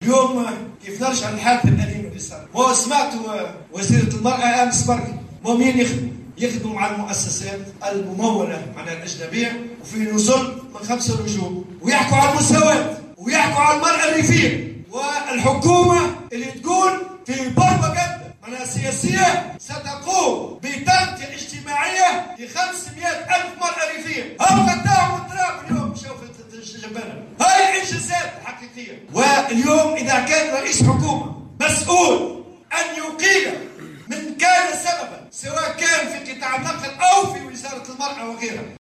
وسخر الطبوبي، في كلمة ألقاها خلال اشغال المؤتمر العادي للاتحاد الجهوي للشغل بتطاوين، اليوم السبت، من ادعاءات الحكومة والمسؤولين حول توفير التغطية الإجتماعية للمرأة الريفية وعملهم على تحقيق المساواة، مضيفا قوله " يقيمون في نزل من فئة 5 نجوم وينظمون تظاهرات مموّلة من مؤسسات أجنبية للحديث عن المساواة وحقوق المرأة الريفية.. وها أننا اليوم نرى الإنجازات تتحقق على أرض الواقع".